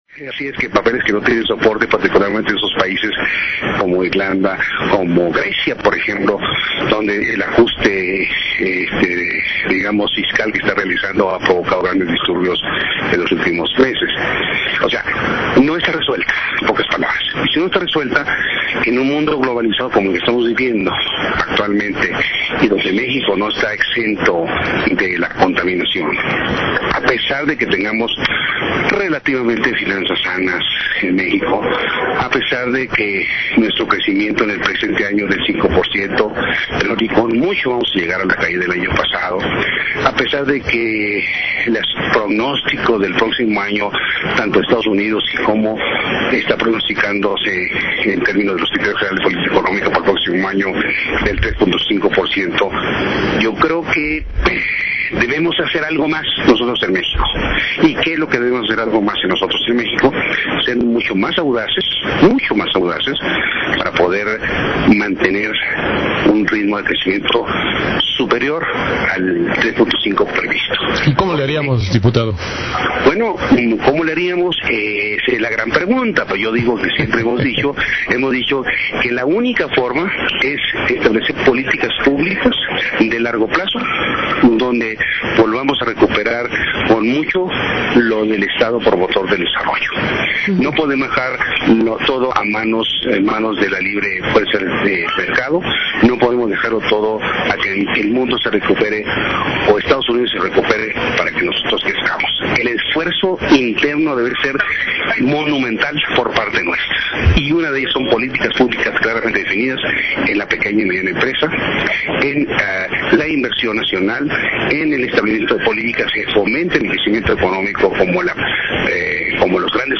22-11-10 Entrevista en Nucleo Radio Mil